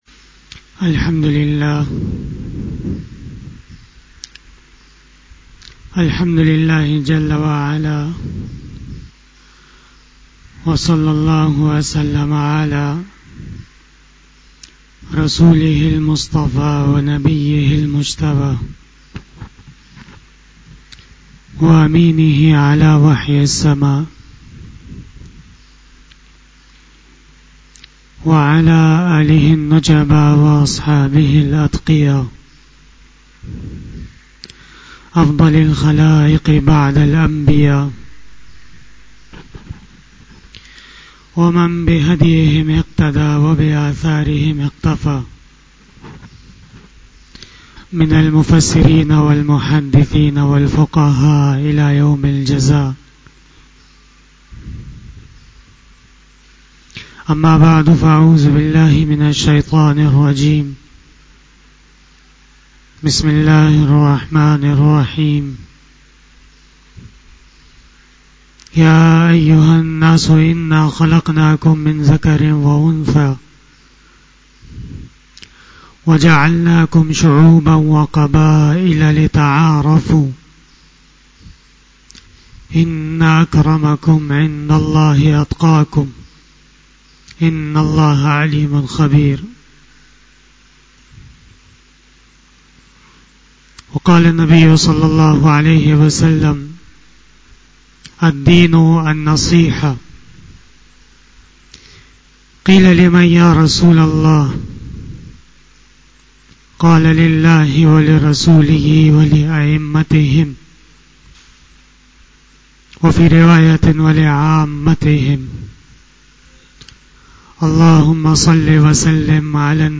17 BAYAN E JUMA TUL MUBARAK 23 April 2021 10 Ramadan 1442H)